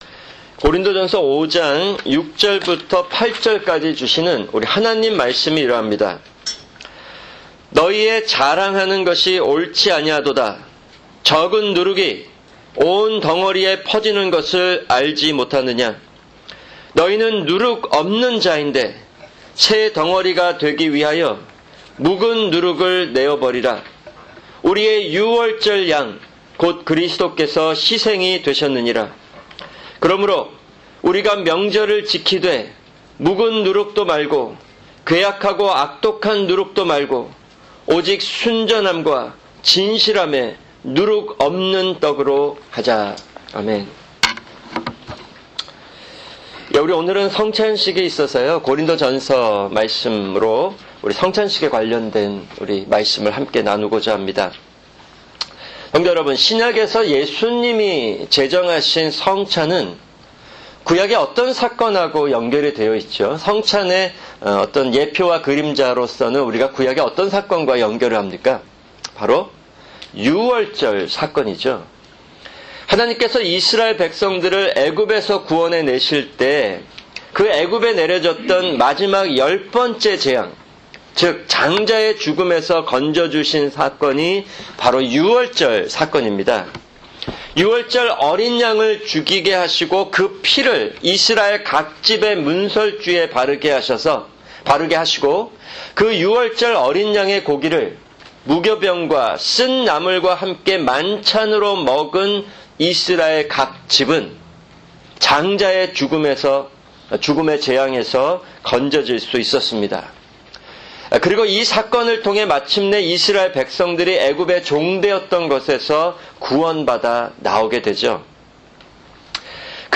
[주일 설교] 고린도전서 5:6-8